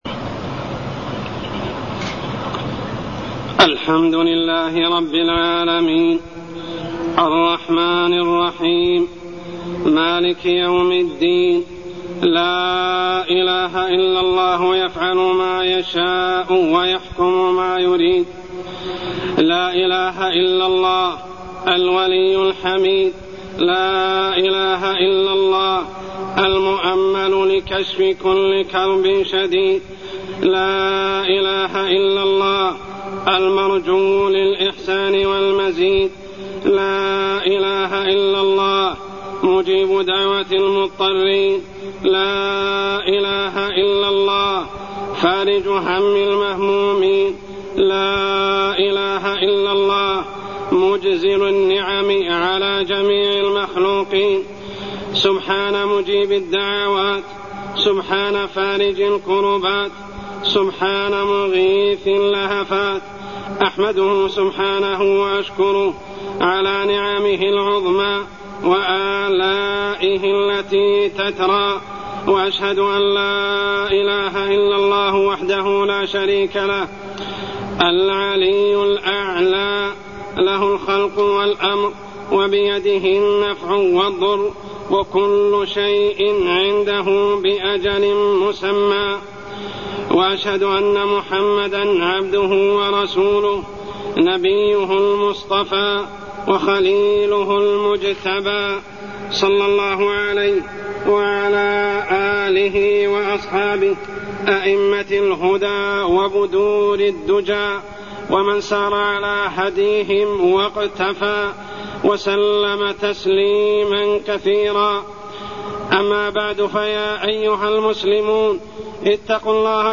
تاريخ النشر ٣٠ شعبان ١٤٢٢ هـ المكان: المسجد الحرام الشيخ: عمر السبيل عمر السبيل التعلق بالمخلوقين